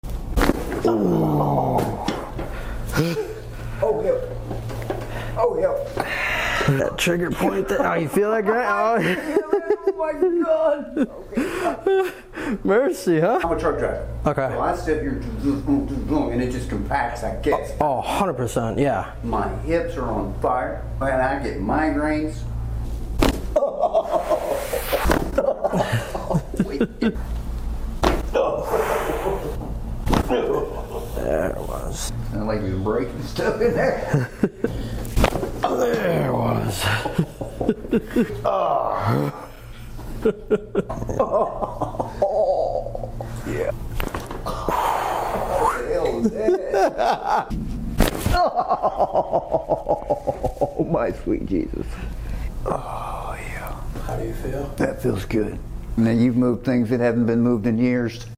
MOST Extreme CRACKS In The Sound Effects Free Download